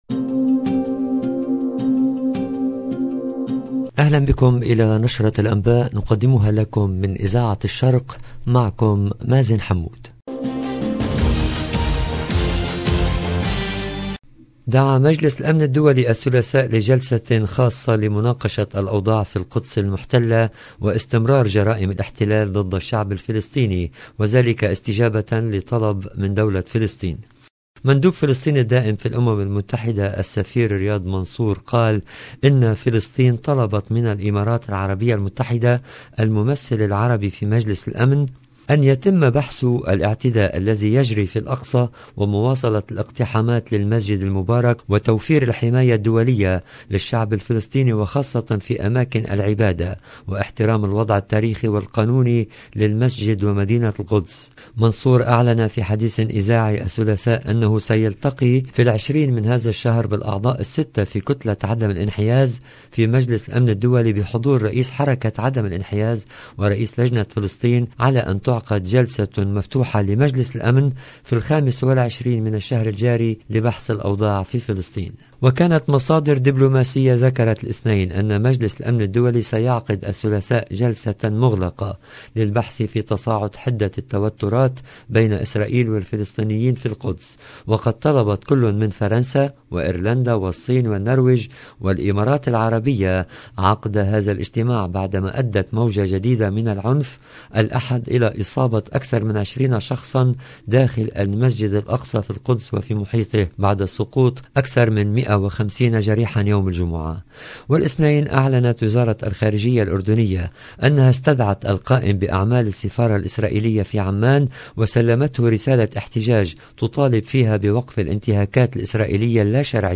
LE JOURNAL DU SOIR EN LANGUE ARABE DU 19/04/22